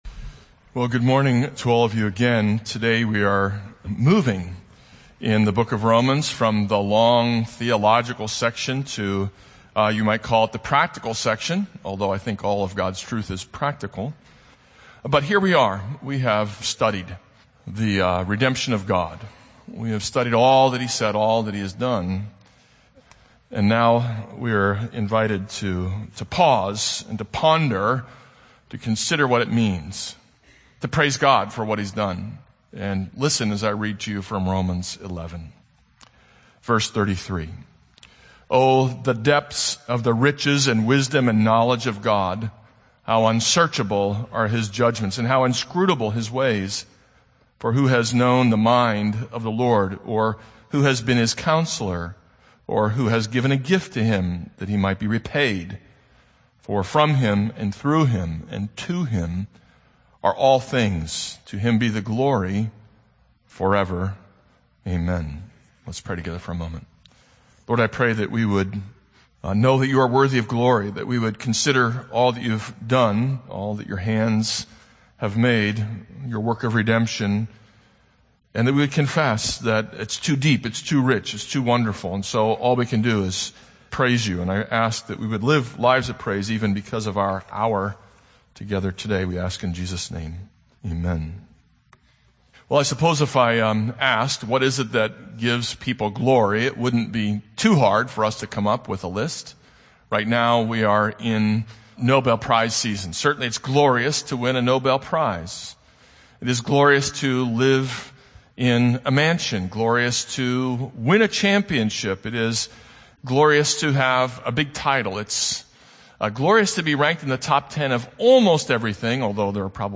This is a sermon on Romans 11:33-36.